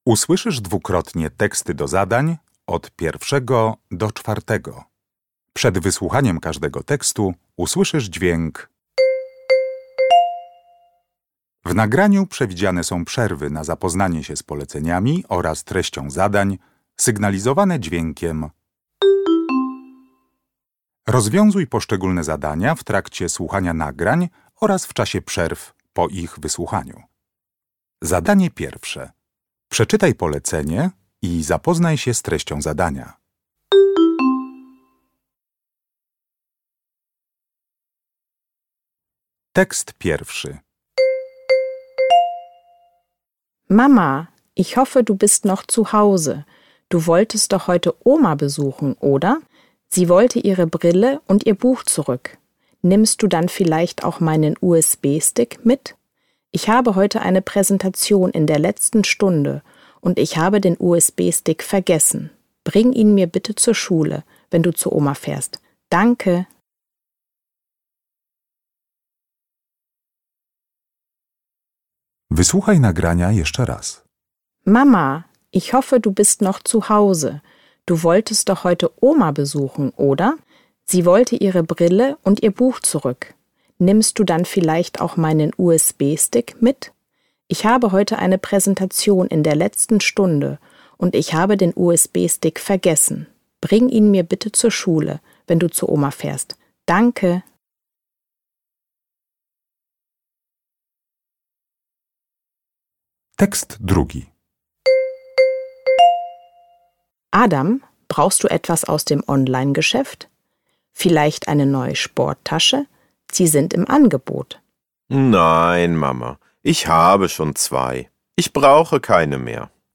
Uruchamiając odtwarzacz z oryginalnym nagraniem CKE usłyszysz dwukrotnie pięć tekstów.